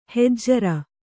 ♪ hejjara